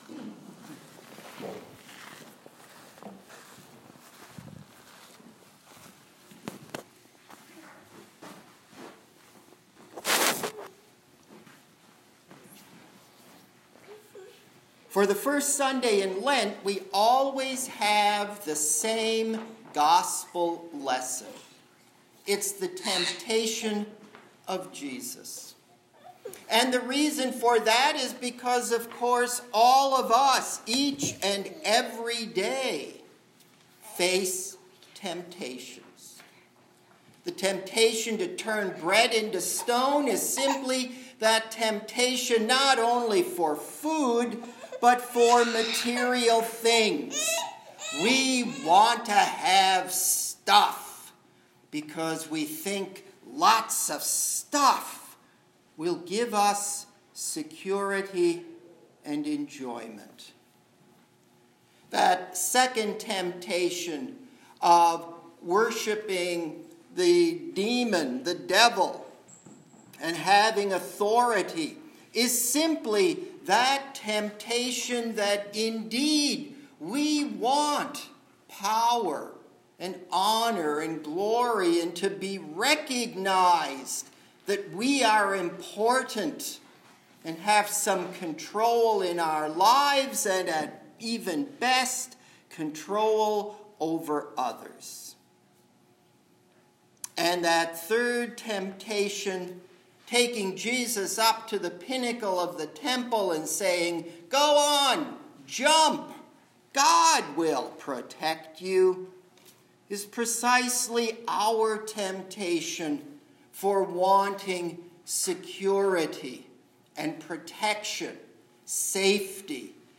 THE CROSS IS NOT DEAD WOOD: A Sermon